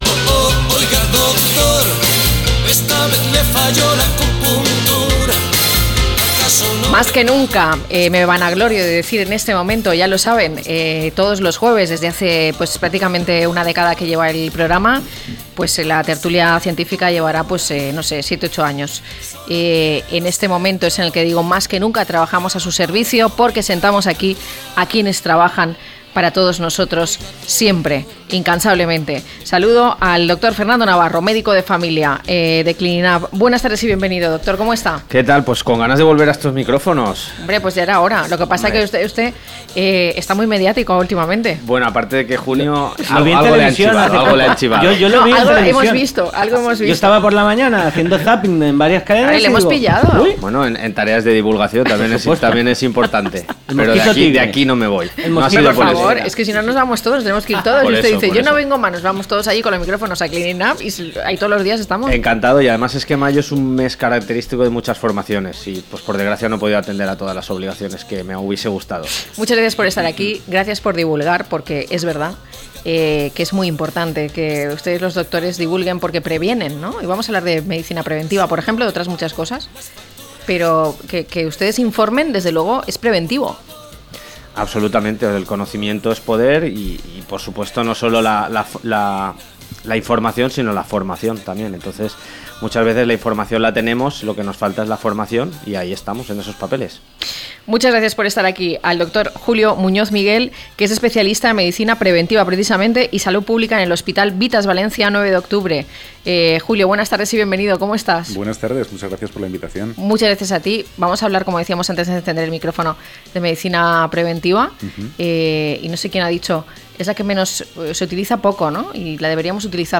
0627-LTCM-TERTULIA-MEDICA.mp3